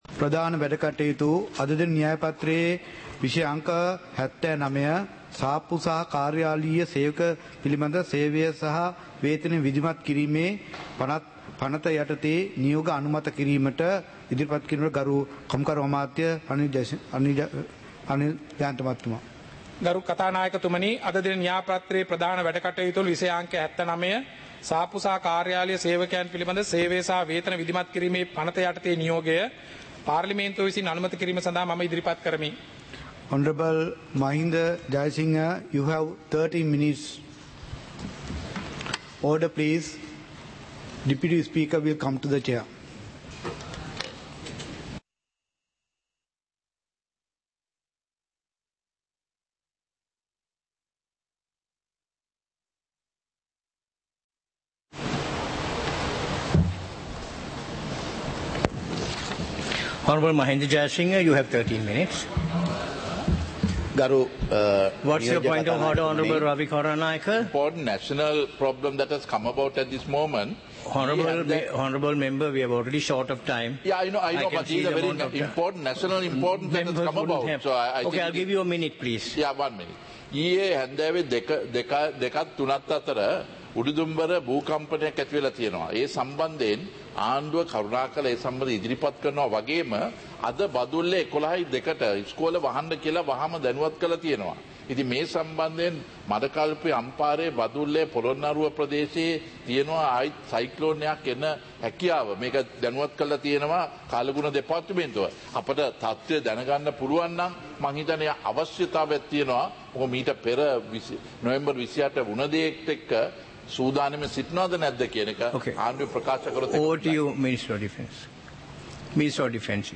இலங்கை பாராளுமன்றம் - சபை நடவடிக்கைமுறை (2026-01-09)